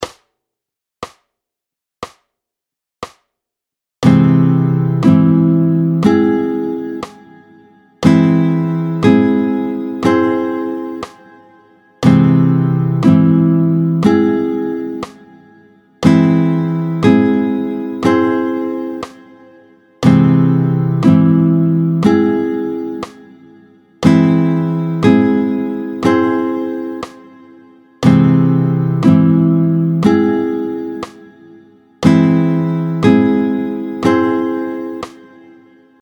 24-05 Do augmenté, tempo 60